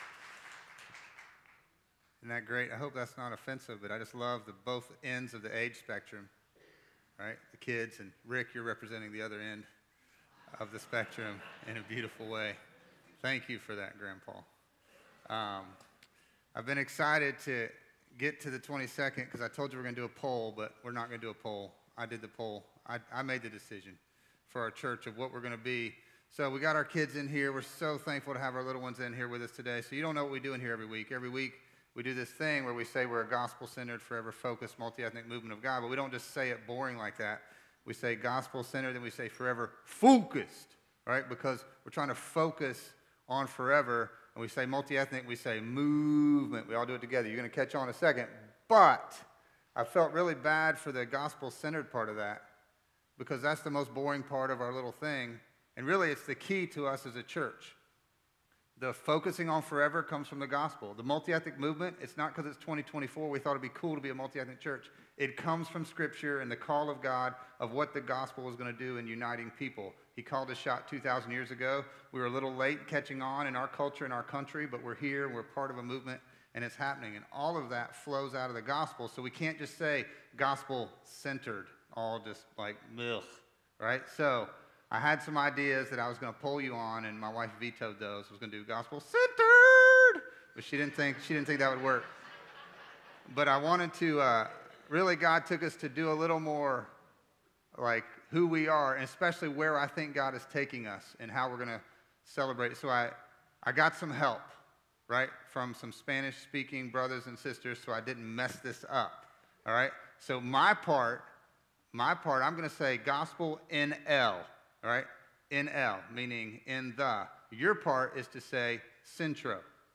A message from the series "The Gift."